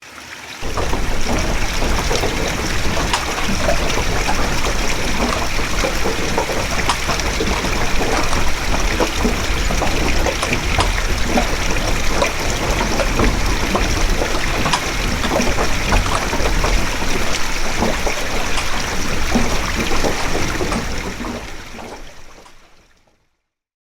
Watermill
Watermill is a free nature sound effect available for download in MP3 format.
Watermill.mp3